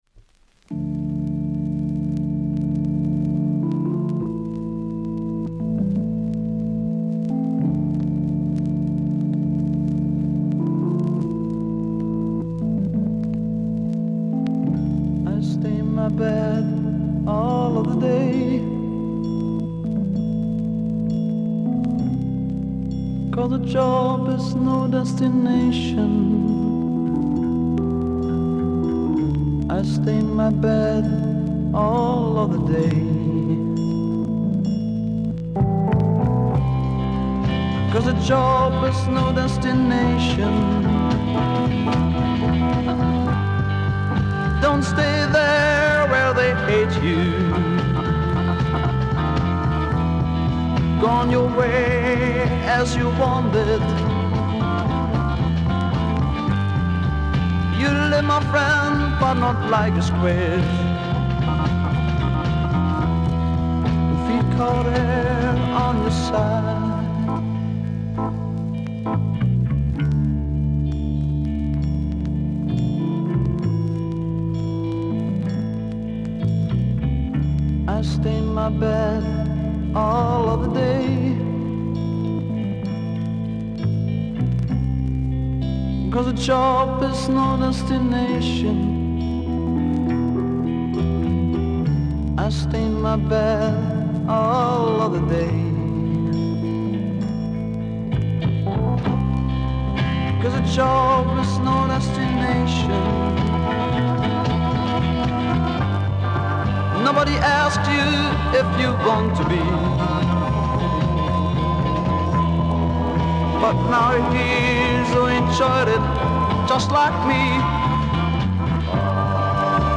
German Freakbeat Psych